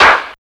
150UKCLAP1-R.wav